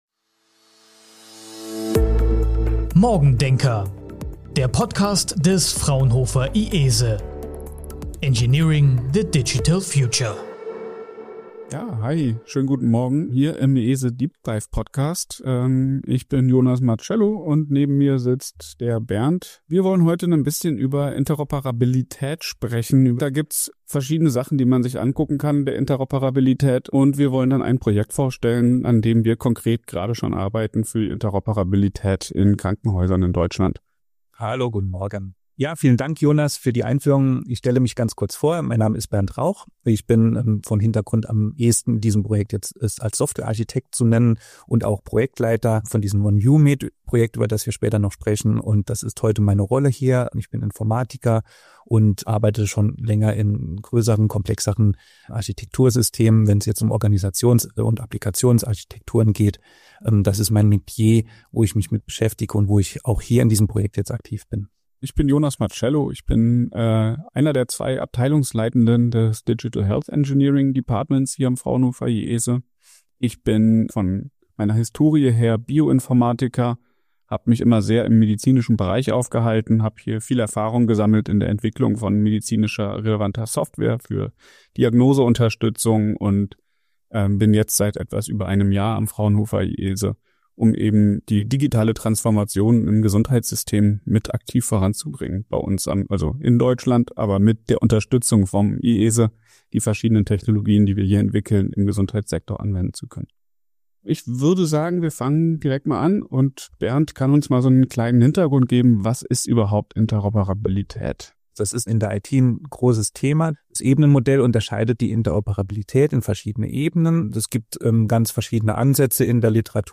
unterhalten sich über das Projekt OneViewMed und geben Einblicke in die Zukunft der Digitalisierung im Gesundheitswesen.